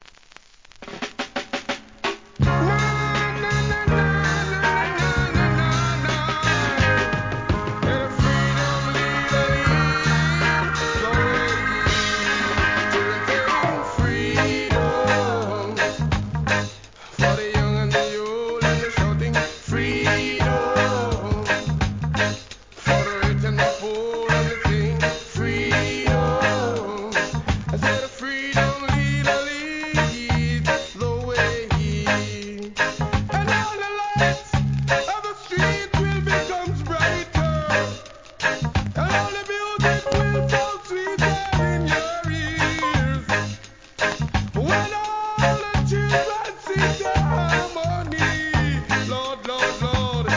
REGGAE
FUNKYなイントロが印象的な1976年作品!!